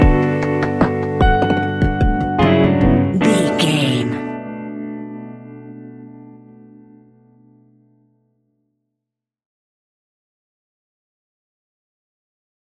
Ionian/Major
B♭
laid back
Lounge
sparse
new age
chilled electronica
ambient
atmospheric
morphing